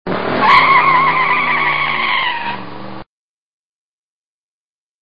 Vous pouvez télécharger le fichier MP3 (clic droit / * ) Prises de sons réalisées par les étudiants de l'Insa de Lyon
autoderapage!!s.mp3